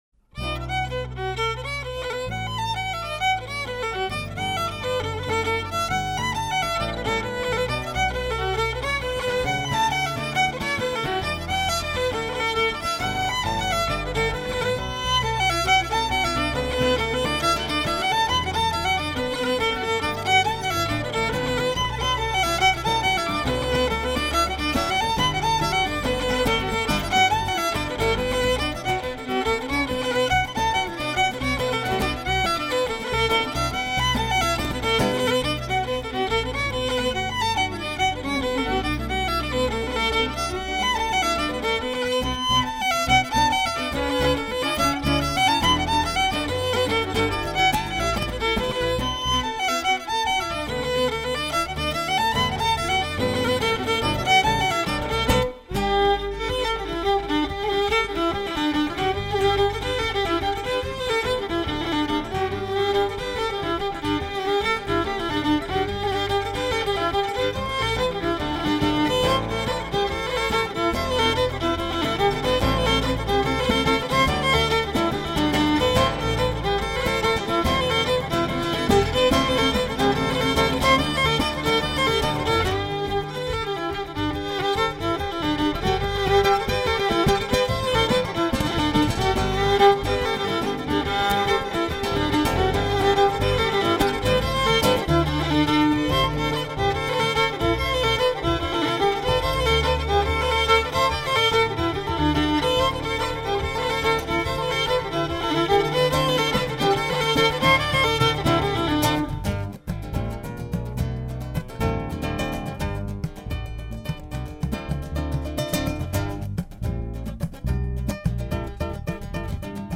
Fiddles.
Bodhran.
Guitars, bass, banjo.